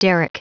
Prononciation du mot derrick en anglais (fichier audio)
Prononciation du mot : derrick